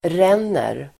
Uttal: [r'en:er]